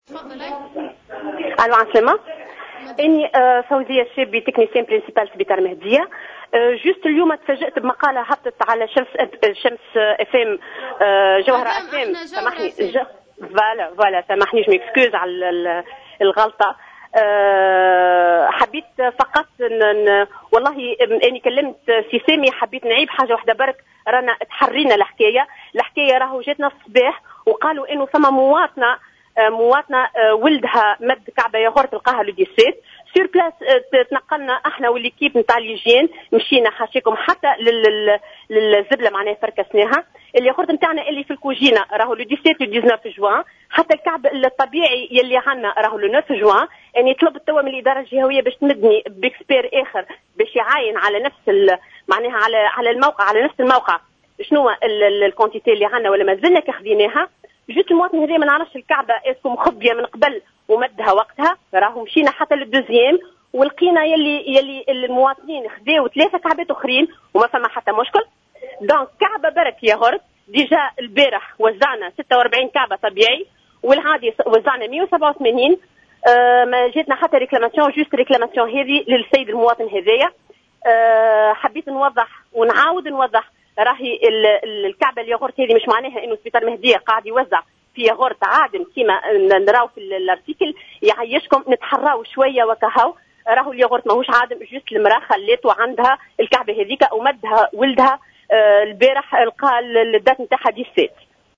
في اتصال هاتفي مع "جوهرة أف أم"